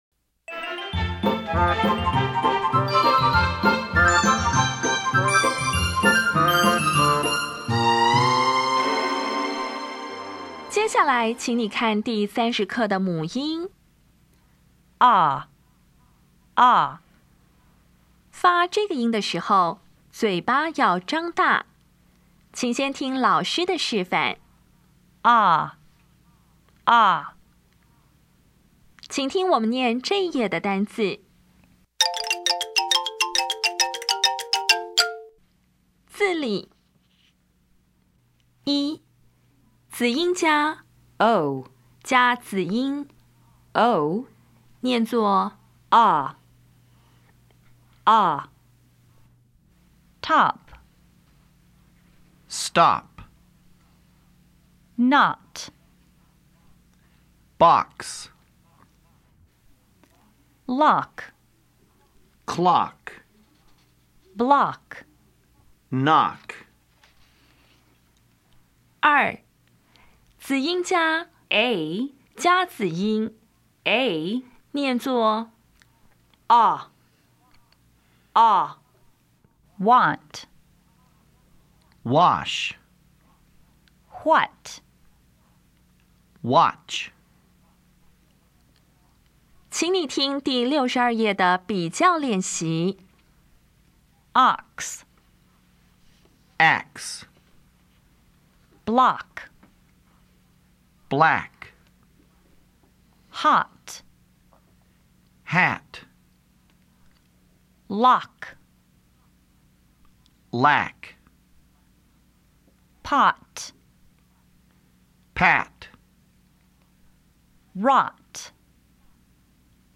音标讲解第三十课
[tɑp]
比较[ɑ] [æ]